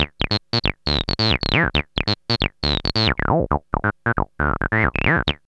BASS_L_4.wav